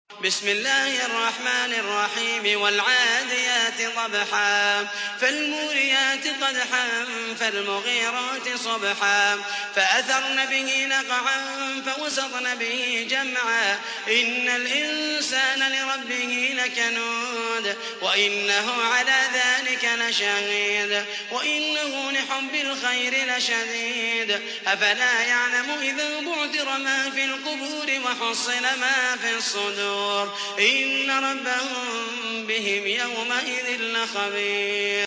تحميل : 100. سورة العاديات / القارئ محمد المحيسني / القرآن الكريم / موقع يا حسين